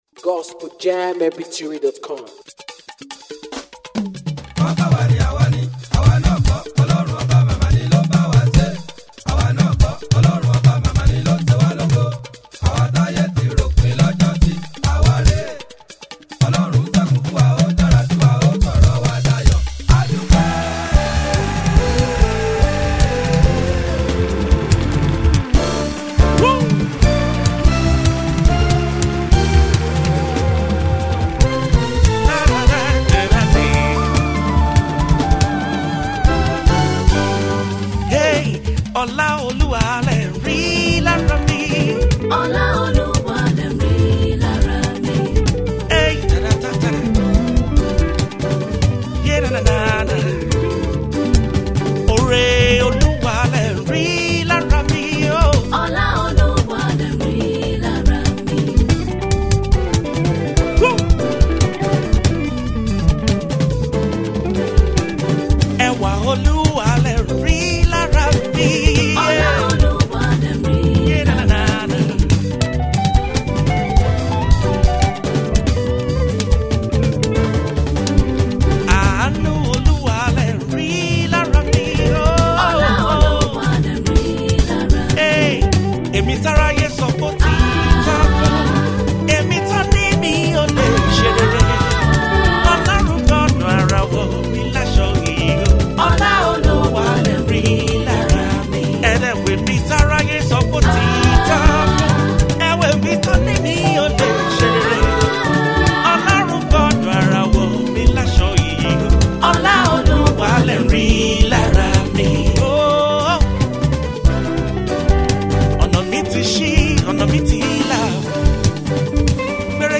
gospel/praise song